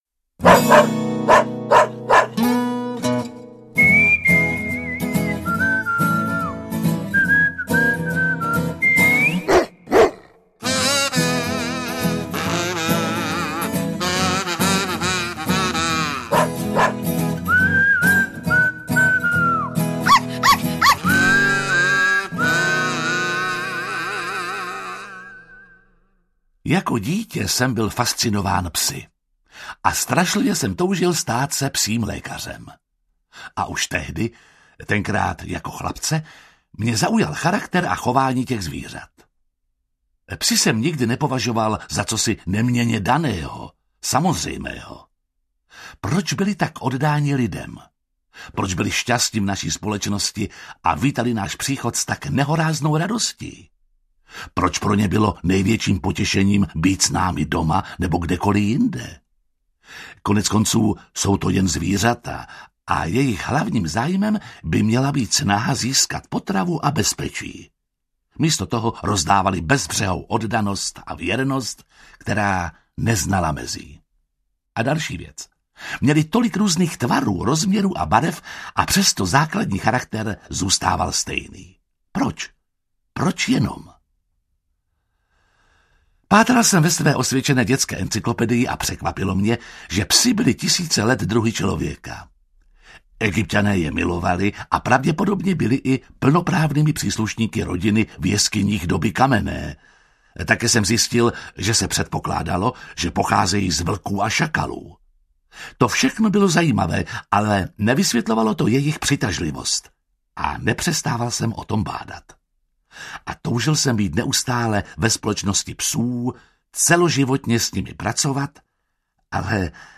Zvěrolékař a psí historky audiokniha
Jiří Lábus čte příběhy o psech z knihy populárního britského spisovatele a veterináře Jamesa Herriota
Ukázka z knihy